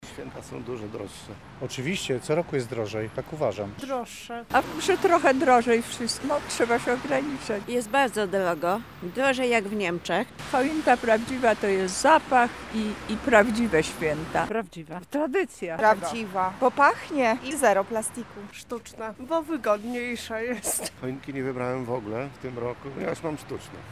Zapytaliśmy mieszkańców Lublina, czy według nich w tym roku przygotowania do tego magicznego czasu bardziej nadszarpnęły ich budżet, oraz na jaką choinkę się zdecydowali:
SONDA